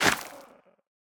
Minecraft Version Minecraft Version snapshot Latest Release | Latest Snapshot snapshot / assets / minecraft / sounds / block / soul_soil / break6.ogg Compare With Compare With Latest Release | Latest Snapshot